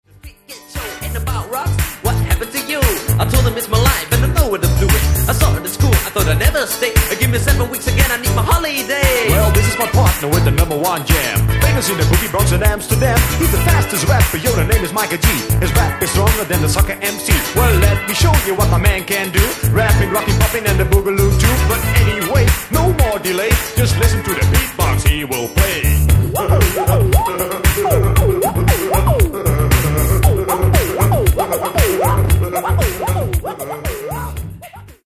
Genere:    Disco | Funk